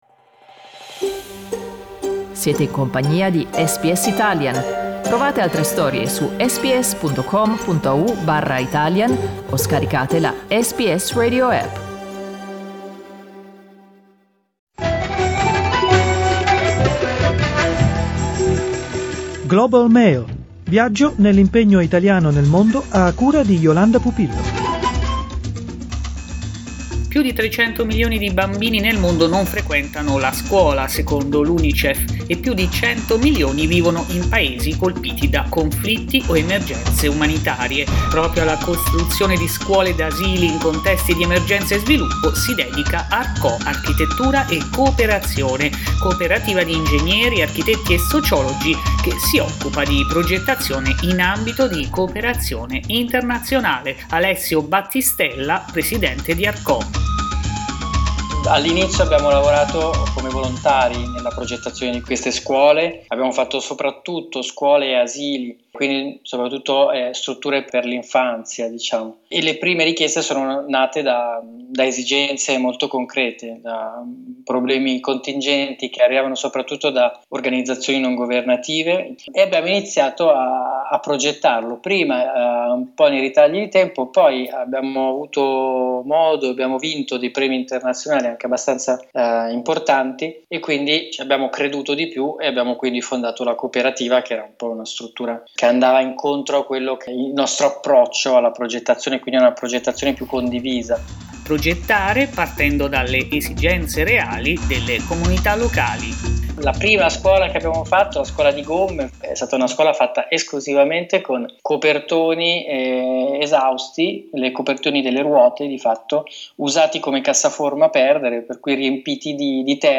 In un'intervista con SBS Italian